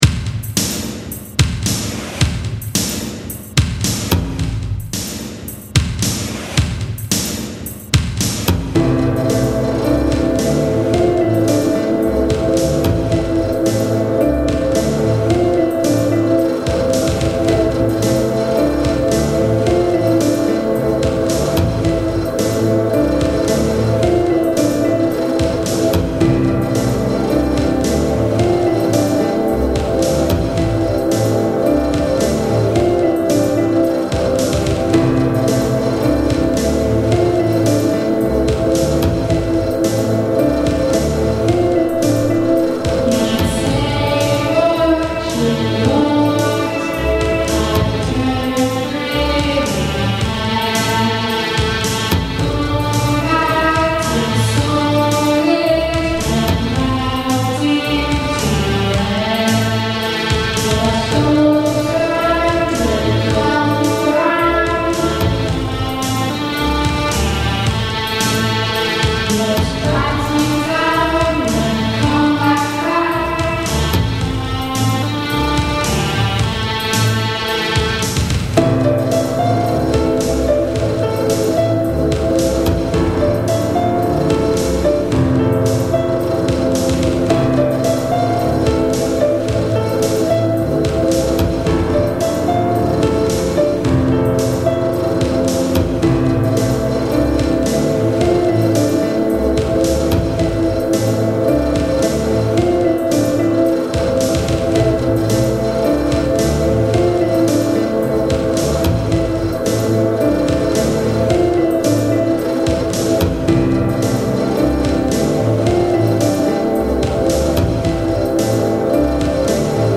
vocals
recorded at the garage, corsham